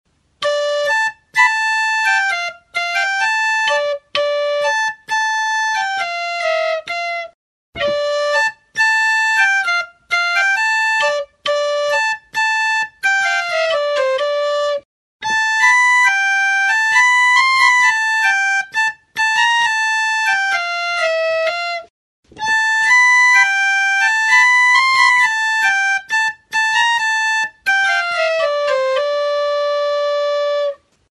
Grabado con este instrumento.
Do tonuko eskala diatonikoa (+ erdiko SI b) ematen du.
Metalezko hodiak ditu eta larruzko hauspoa.